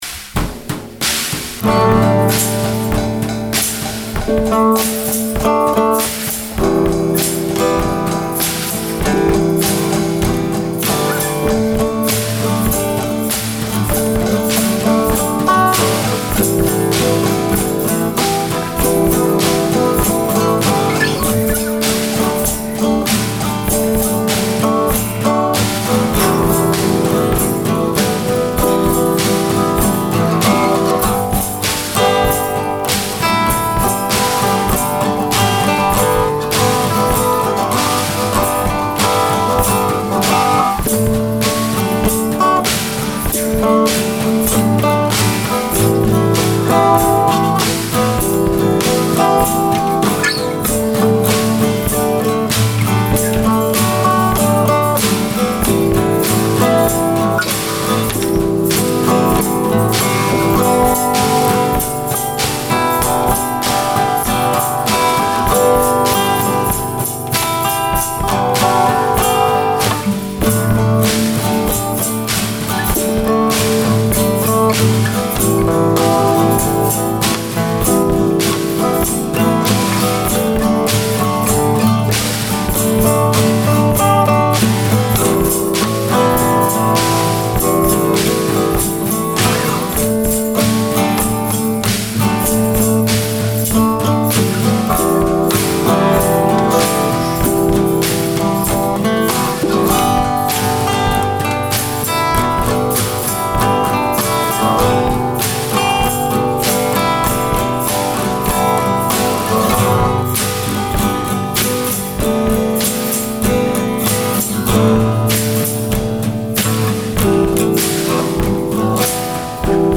Here is a melancholic little piece.